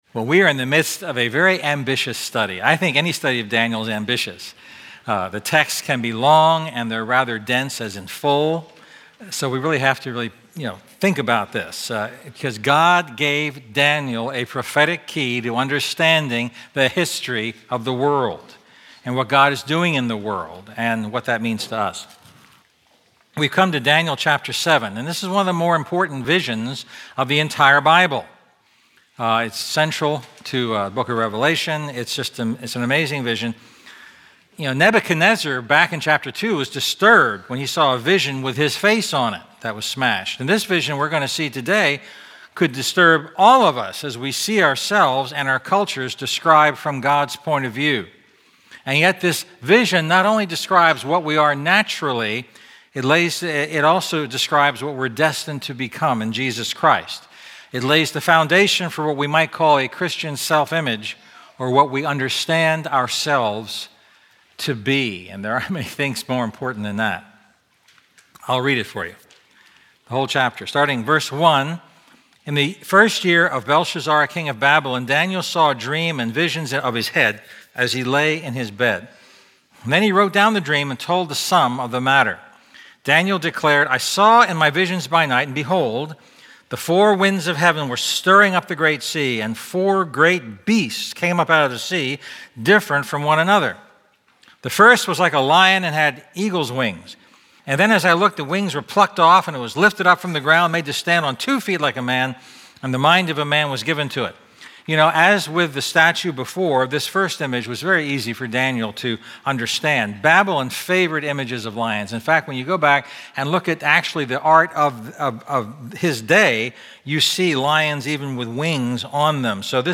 A message from the series "A Larger Faith."